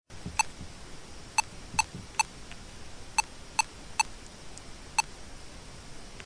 Laptop without RAM beeps!... It's also a bit arty!